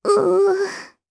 Annette-Vox_Sad_jp.wav